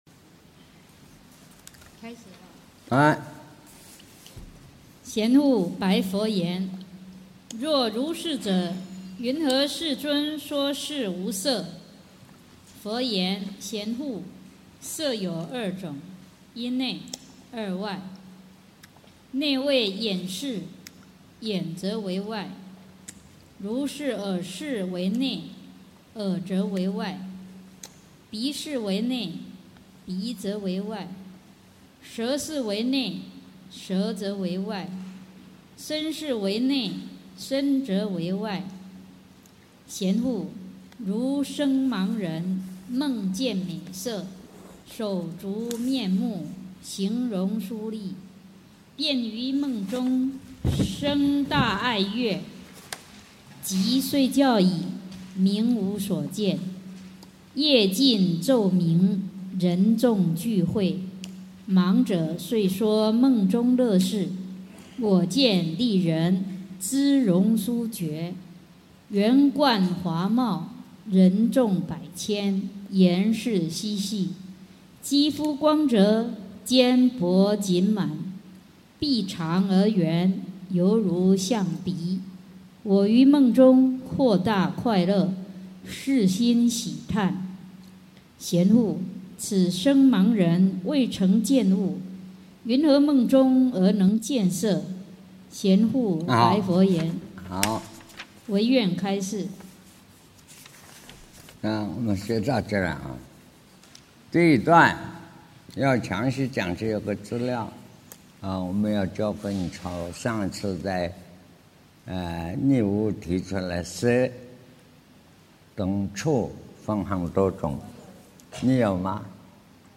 识与色 南怀瑾先生讲大乘显识经(4)